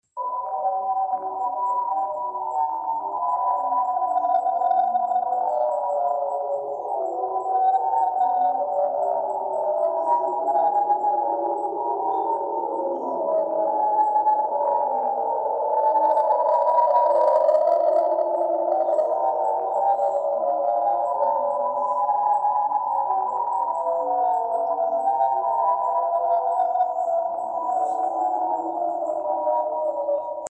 This is the sound of several galaxies that reside within the core of the galaxy cluster Abell 370,approximately 4 BILLION light-years away from Earth 🌎🤯